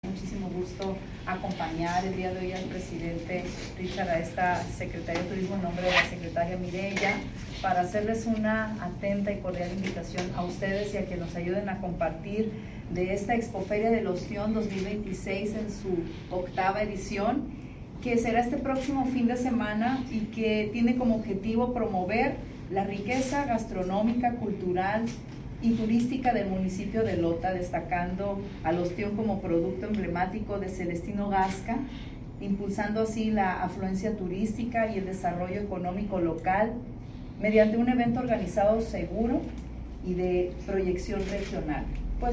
En rueda de prensa, donde se dieron a conocer los detalles de este evento, Celia Jáuregui Ibarra, subsecretaria de Planeación, Inversión y Desarrollo de Sectur, en representación de la secretaria de Turismo, Mireya Sosa Osuna, señaló que la Feria del Ostión impulsa de forma importante la afluencia turística y el desarrollo económico en la entidad.